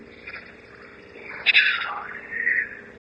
EVP-3 slowed down___
EVP3_SLOW.wav